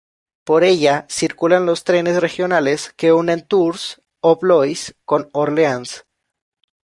Pronunciado como (IPA) /ˈtɾenes/